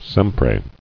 [sem·pre]